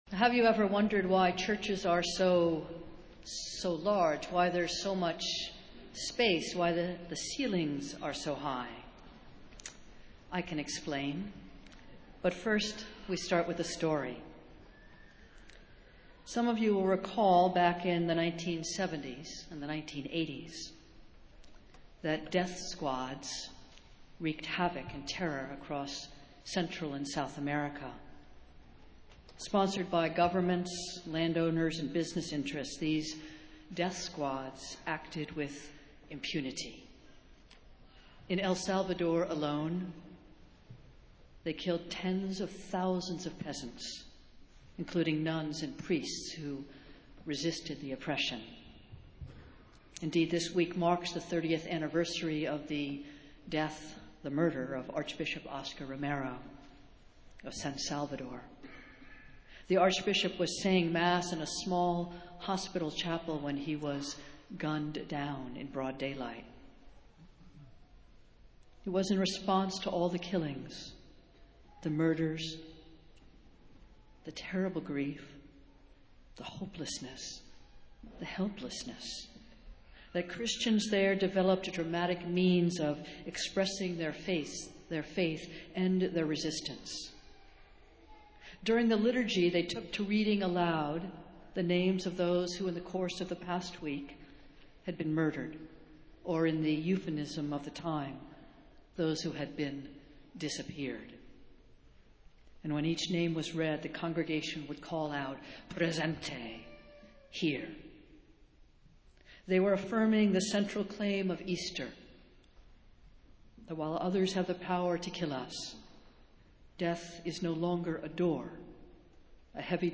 Festival Worship - Easter Sunday